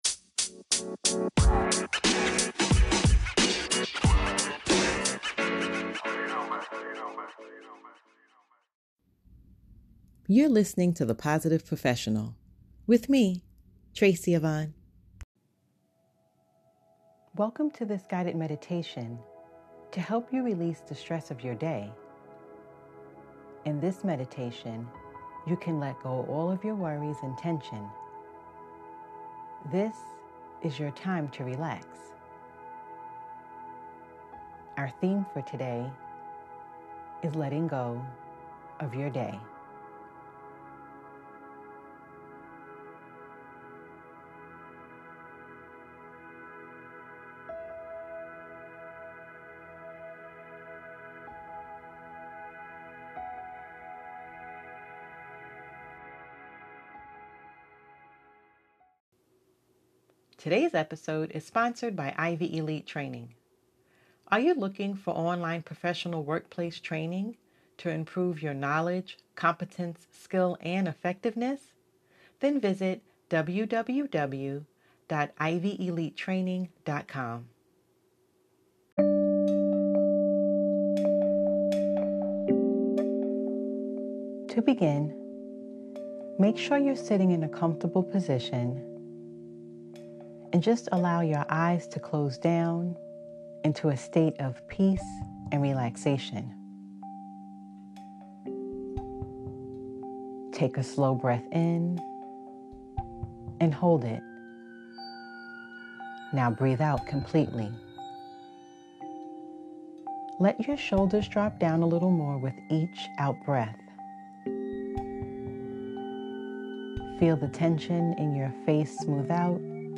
Guided Meditation: Letting Go of The Day.